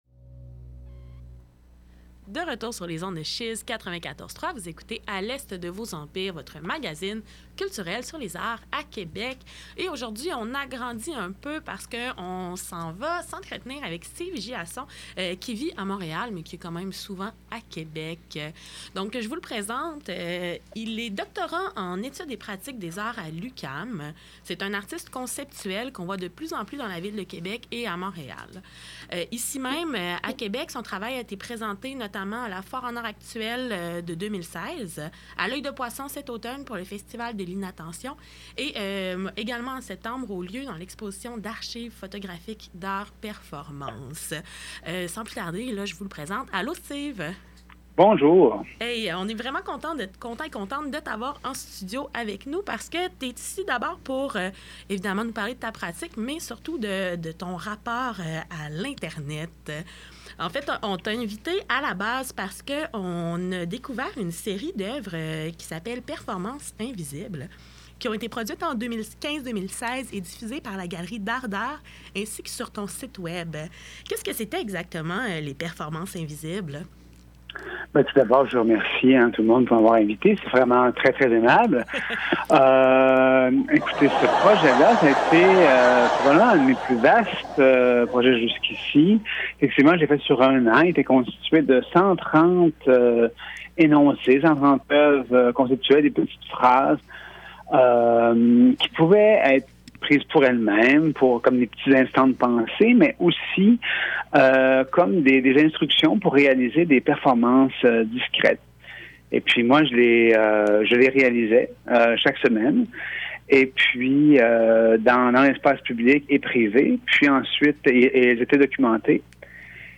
une sculpture sonore constituée de rires enregistrés